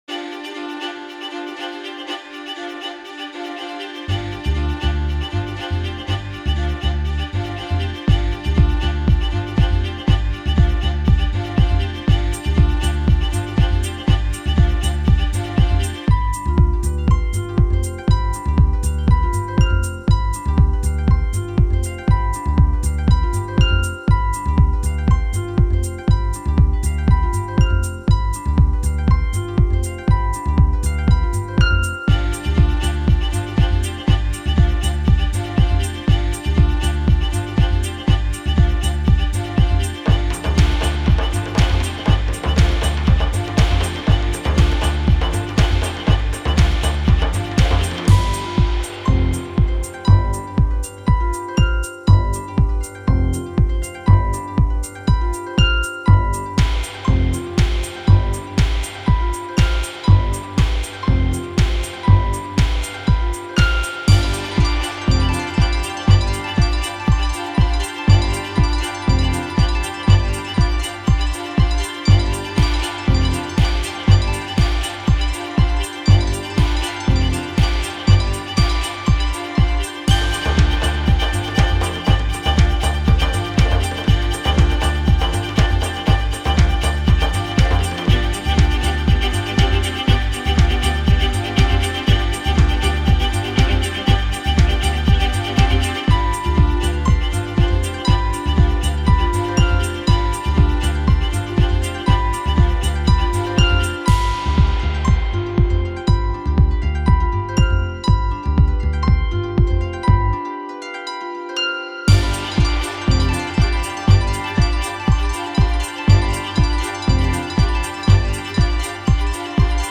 Fiddles jig around the fire, harps weave between the sparks